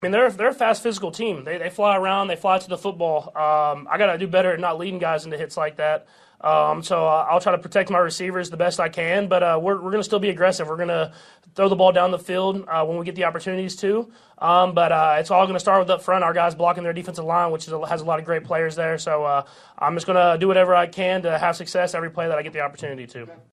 Chiefs quarterback Patrick Mahomes says it begins up front.